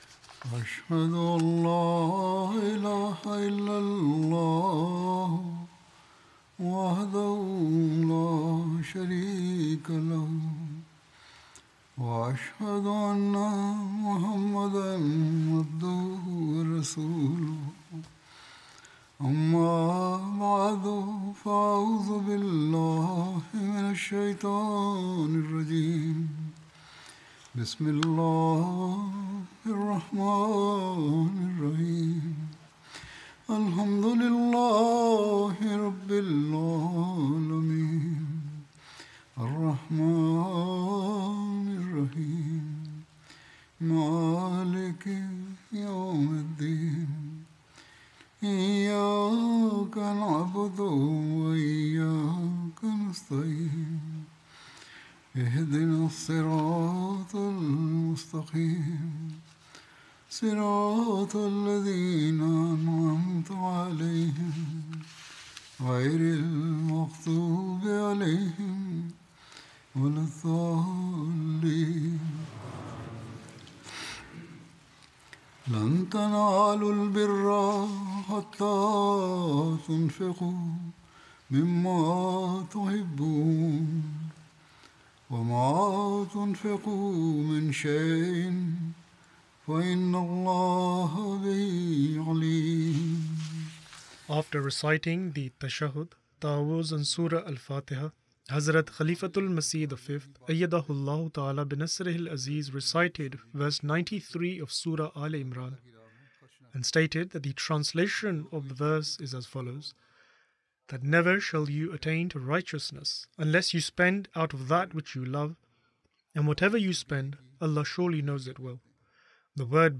English Translation of Friday Sermon delivered by Khalifatul Masih